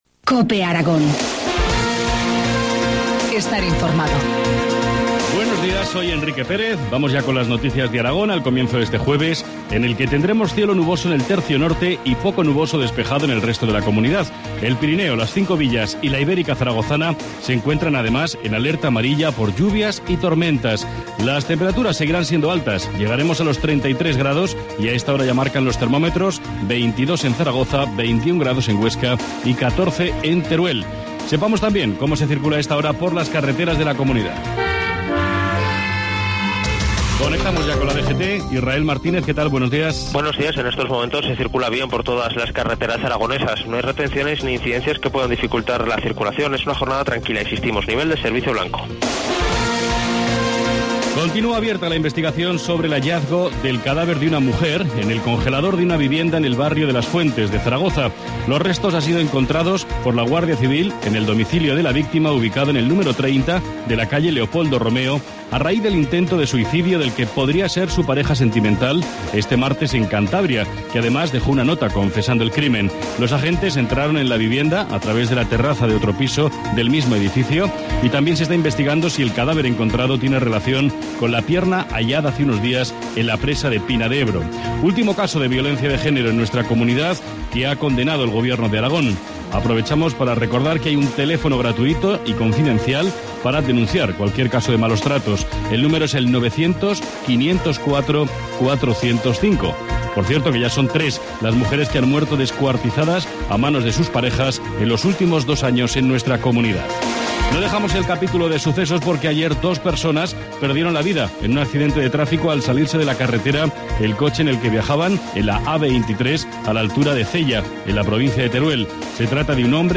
informativo matinal, 13 junio, 7,25 horas